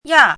chinese-voice - 汉字语音库
ya4.mp3